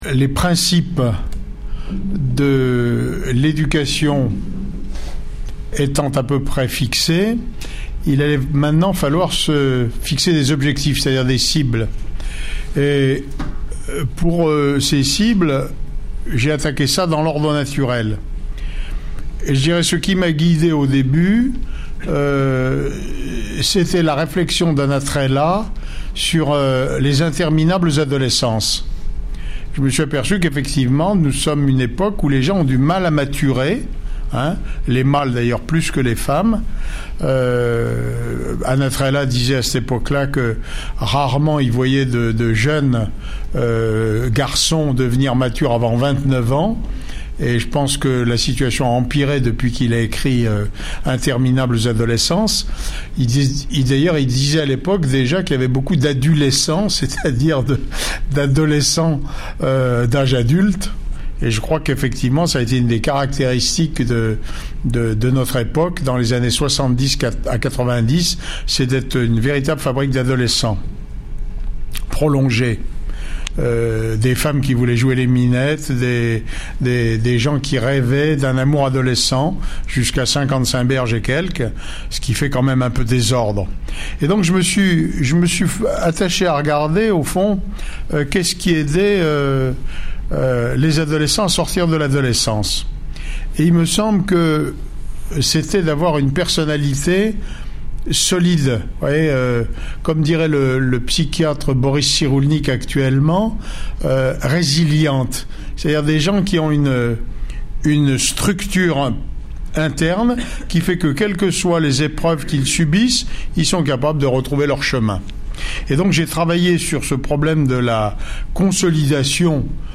Une parole claire et concr�te, � l'usage des parents et des �ducateurs.